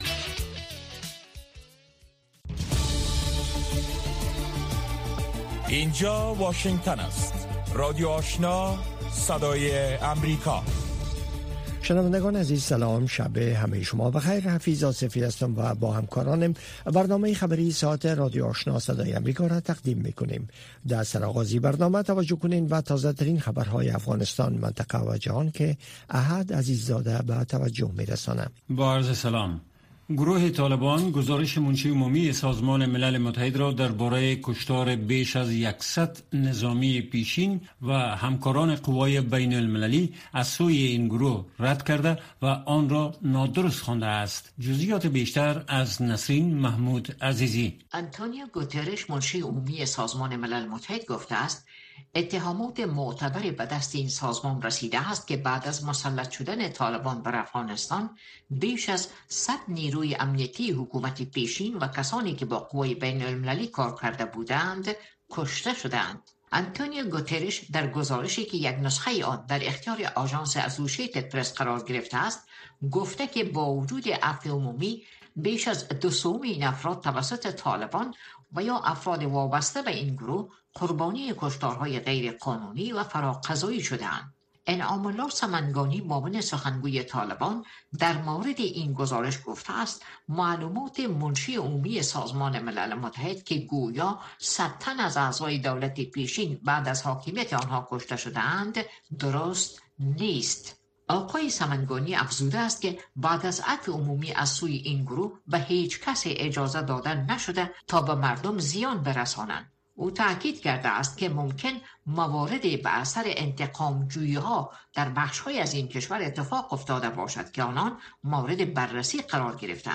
در برنامه خبری شامگاهی، خبرهای تازه و گزارش‌های دقیق از سرتاسر افغانستان، منطقه و جهان فقط در نیم ساعت پیشکش می‌شود.